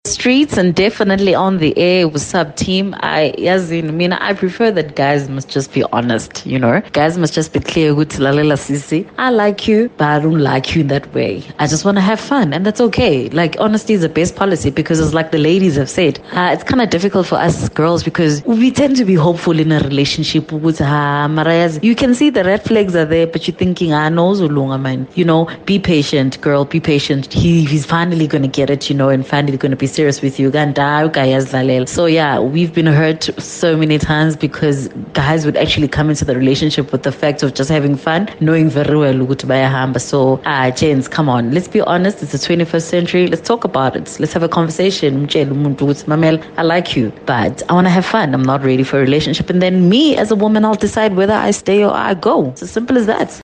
Kaya Drive listeners weigh in on dead-end relationships: